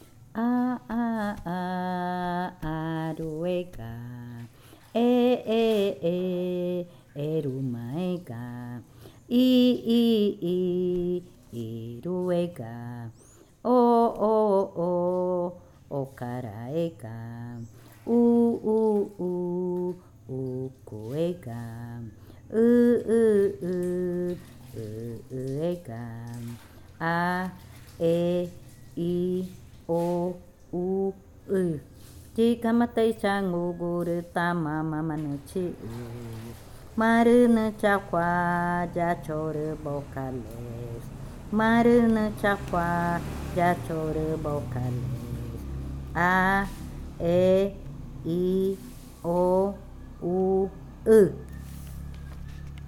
Canción infantil 10. Canción de las vocales
Cushillococha